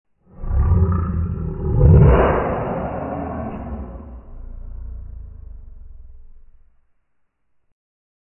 Demon Lion Monster Growl Roar Bouton sonore
The Demon Lion Monster Growl Roar sound button is a popular audio clip perfect for your soundboard, content creation, and entertainment.